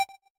blip2.ogg